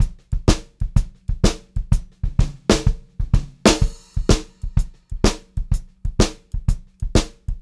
126CLBEAT2-R.wav